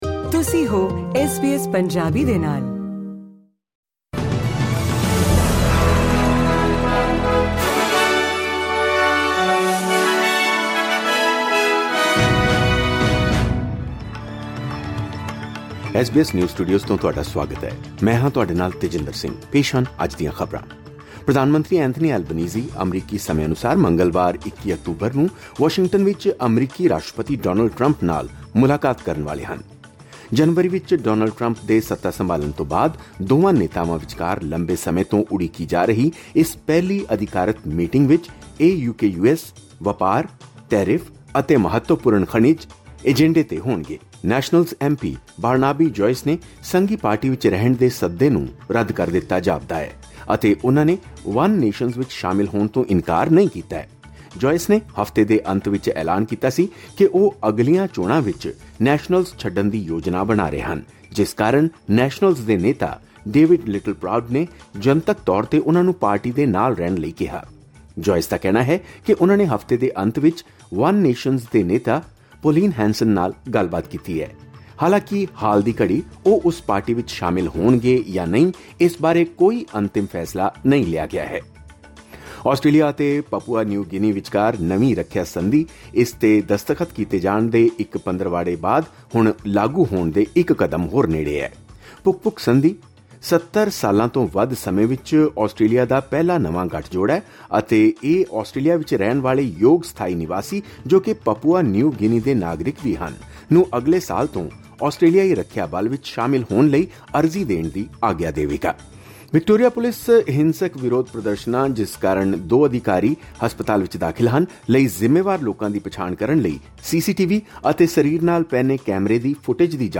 ਖ਼ਬਰਨਾਮਾ: ਪ੍ਰਧਾਨ ਮੰਤਰੀ ਐਂਥਨੀ ਐਲਬਨੀਜ਼ੀ ਦੀ ਵਾਸ਼ਿੰਗਟਨ 'ਚ ਅਮਰੀਕੀ ਰਾਸ਼ਟਰਪਤੀ ਡੋਨਾਲਡ ਟਰੰਪ ਨਾਲ ਪਹਿਲੀ ਅਧਿਕਾਰਤ ਮੁਲਾਕਾਤ